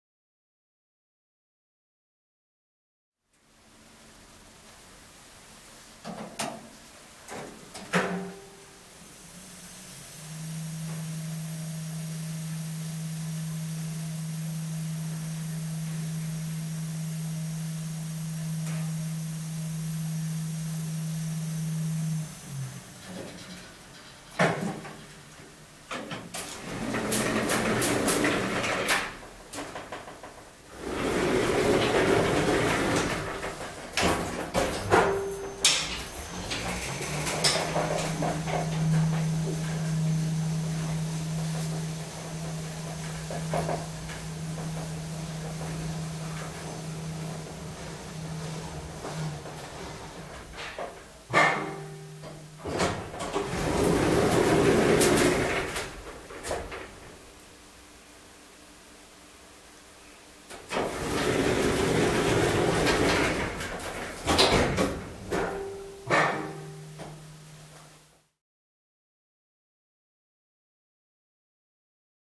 Elevator Lift Moving And Door Closing